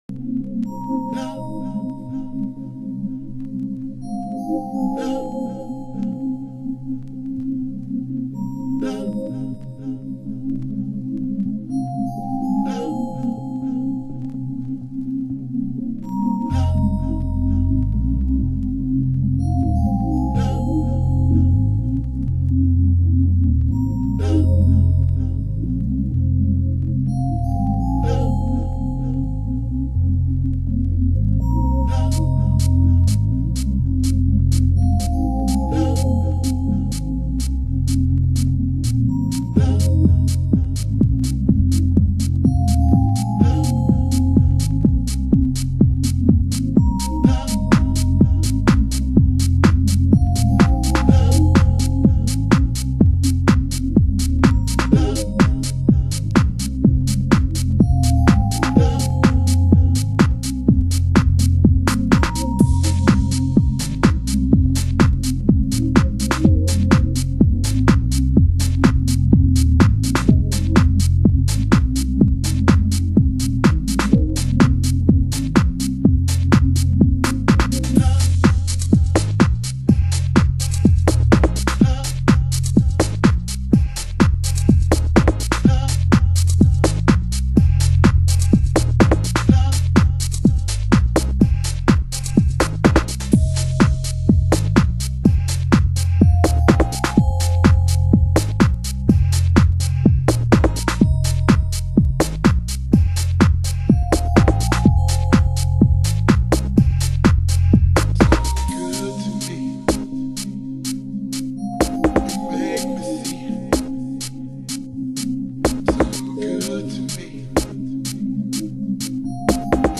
中盤 　　盤質：少しチリパチノイズ有